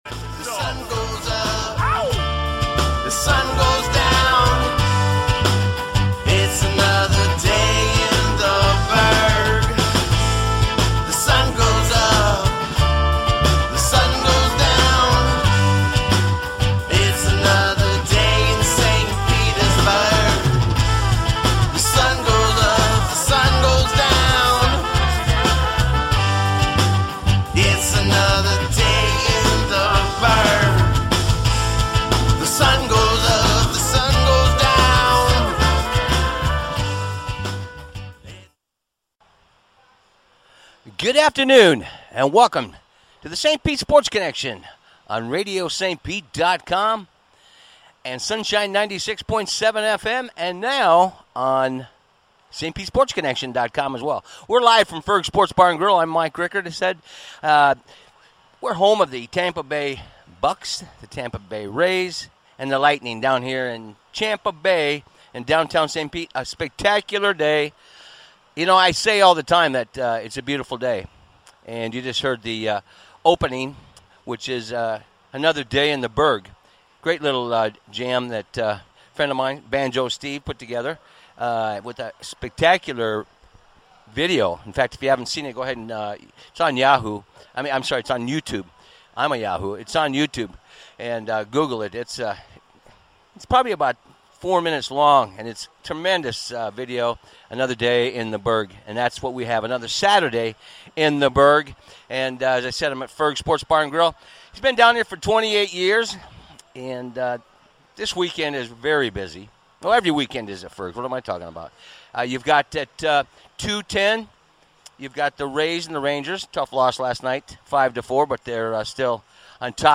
St. Pete Sports Connection 6-5-21 Live from Fergs w